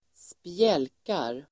Ladda ner uttalet
Uttal: [²spj'äl:kar]